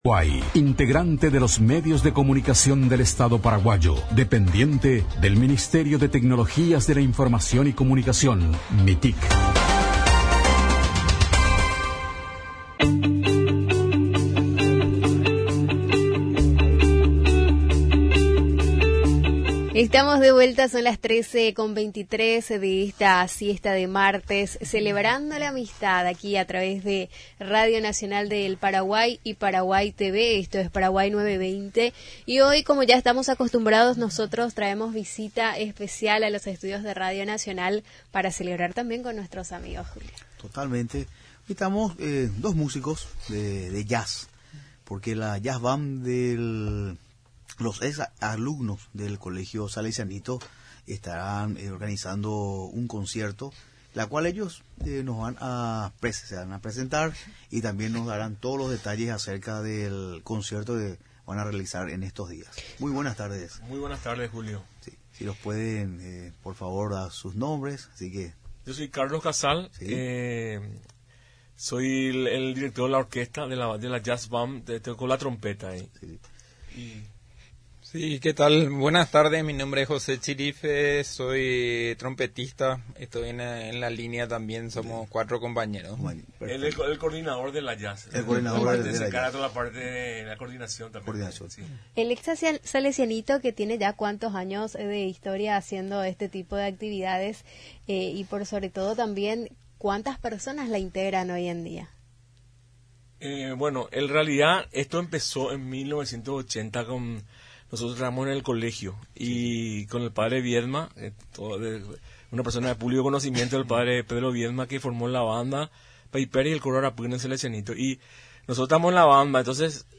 durante su visita a los estudios de Radio Nacional del Paraguay